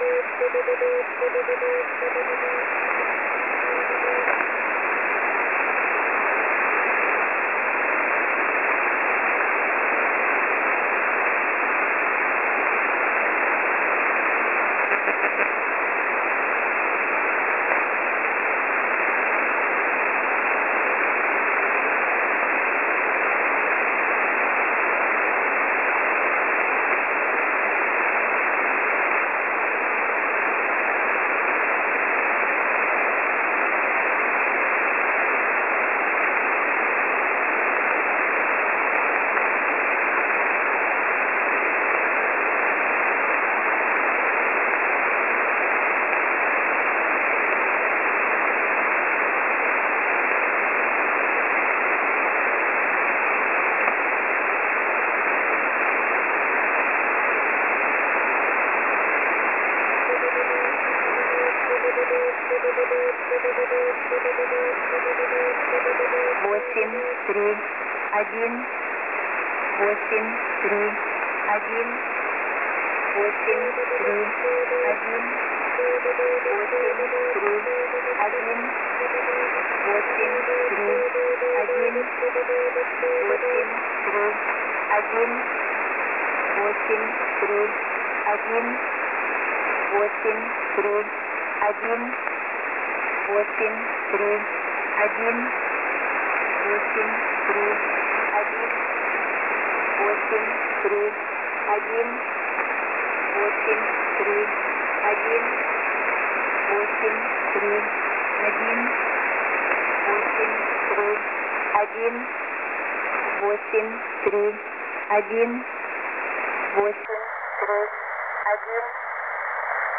Mode: USB + Carrier Frequency: 9145